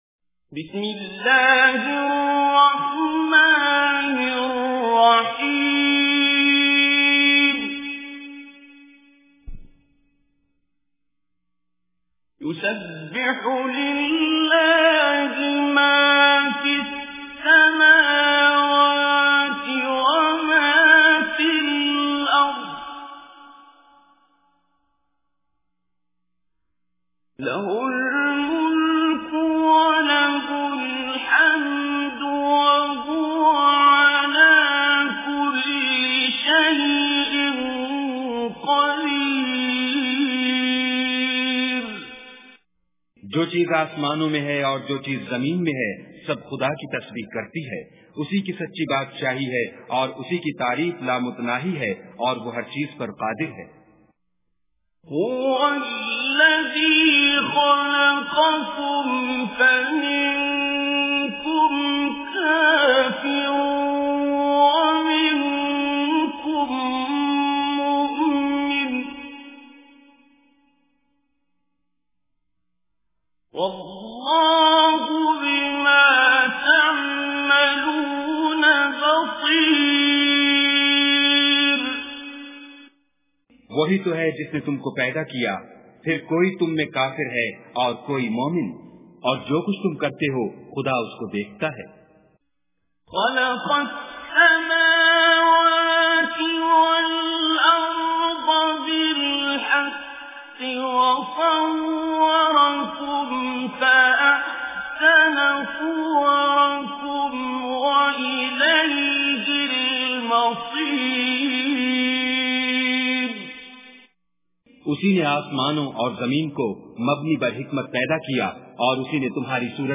Surah Taghabun Recitation with Urdu Translation
Surah Al-Taghabun is 64 surah or chapter of Holy Quran. Listen online or download mp3 tilawat / recitation of Surah Al-Taghabun in the voice of Qari Abdul Basit As Samad.